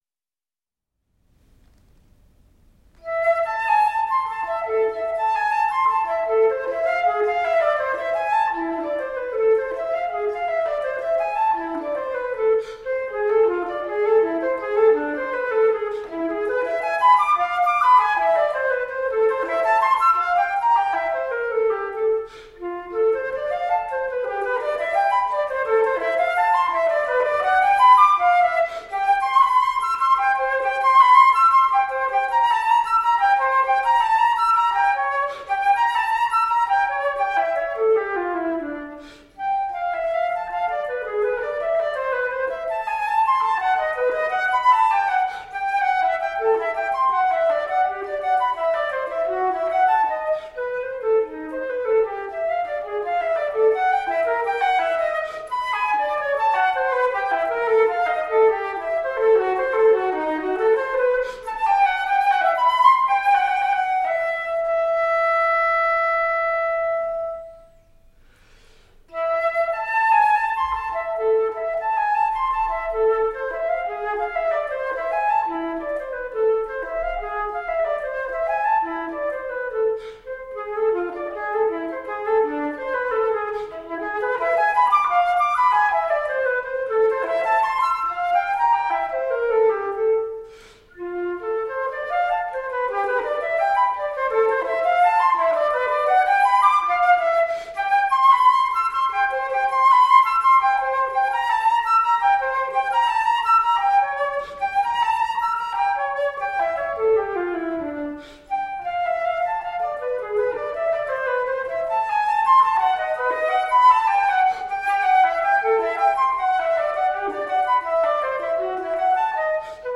Flautists with finesse, intelligence and grooves.
is for unaccompanied flute
recorded at the Hakodate City Community Centre 2011.
Classical, Chamber Music, Baroque, Instrumental